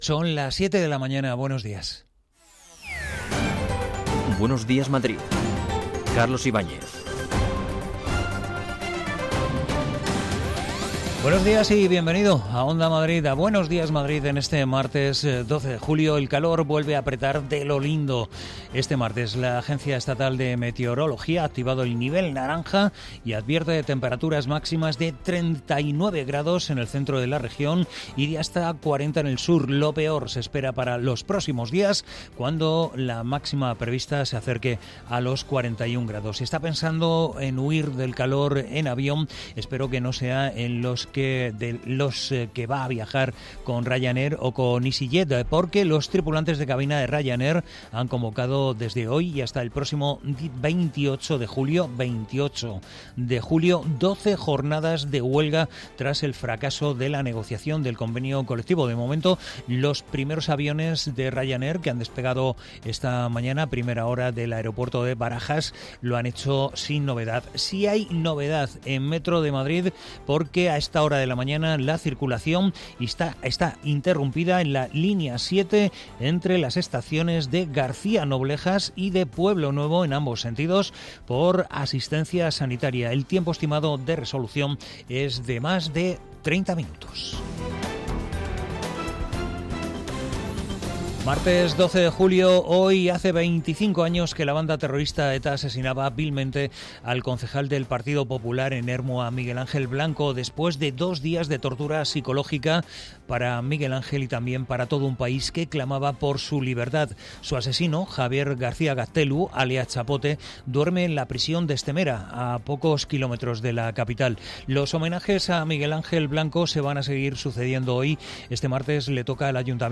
Actualidad, opinión, análisis, información de servicio público, conexiones en directo y entrevistas.